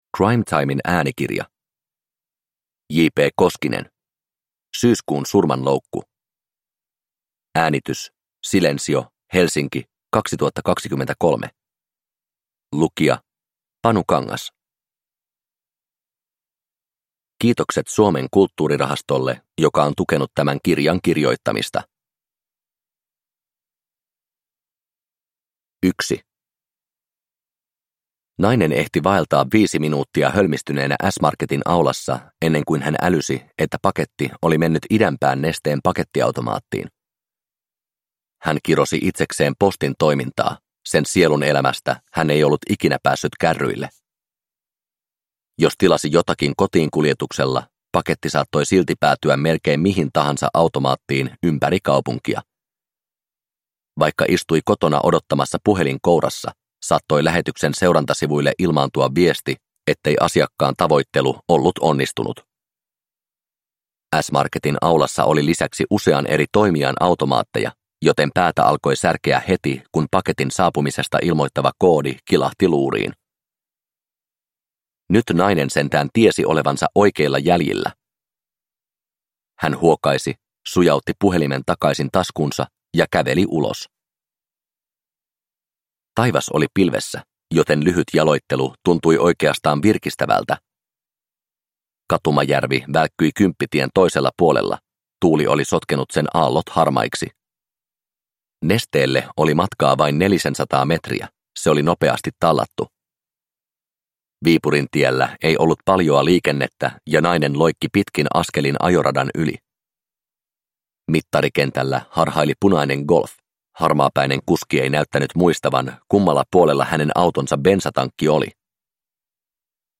Syyskuun surmanloukku – Ljudbok – Laddas ner